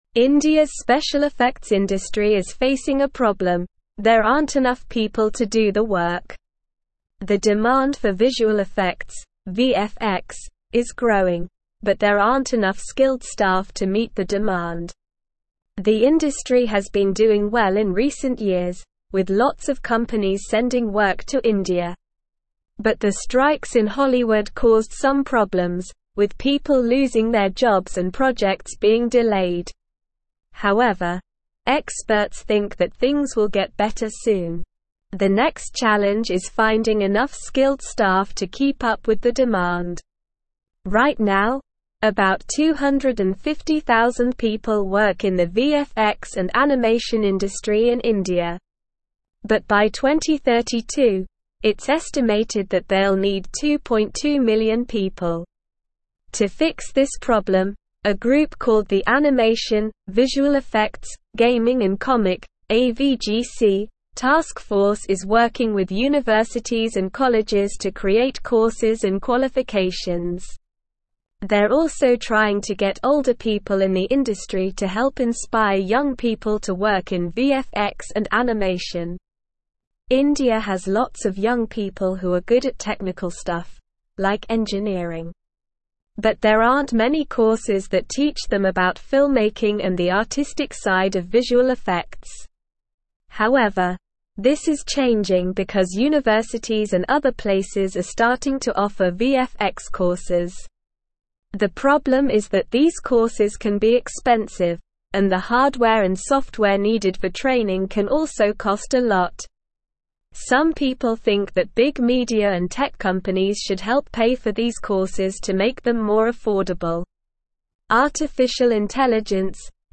Slow
English-Newsroom-Upper-Intermediate-SLOW-Reading-Indias-VFX-Industry-Faces-Staff-Shortage-as-Demand-Grows.mp3